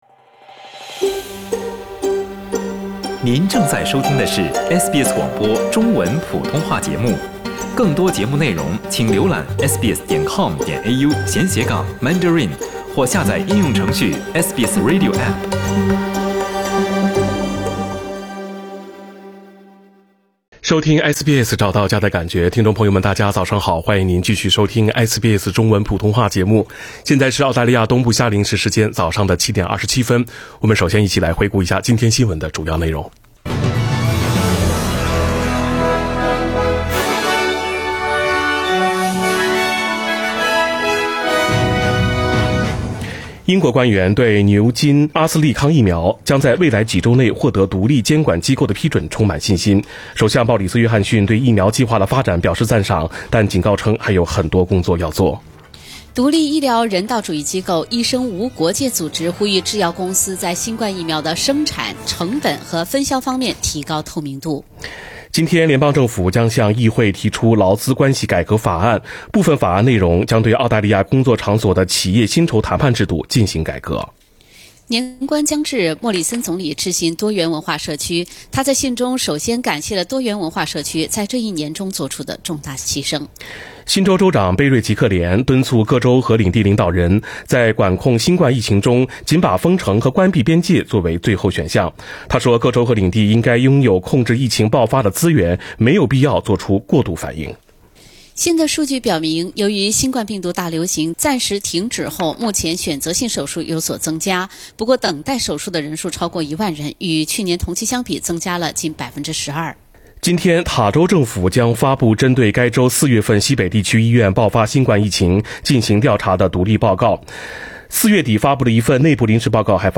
SBS早新聞（12月09日）